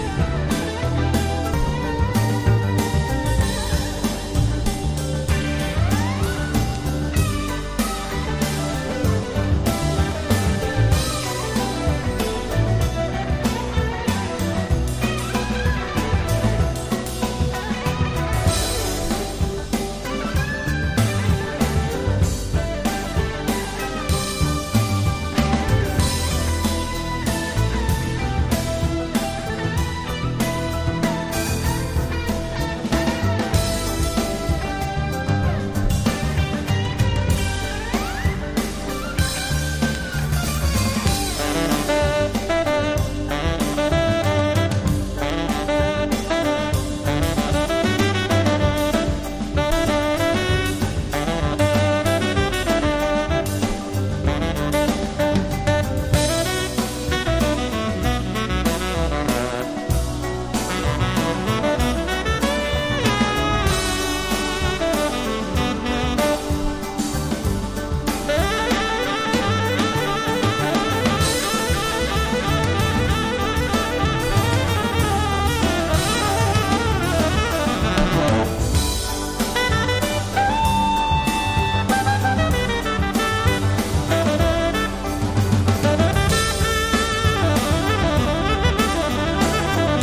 既に何度かの共演を果たしている彼ららしく、リラクシンな雰囲気の演奏が多め。
FUSION / JAZZ ROCK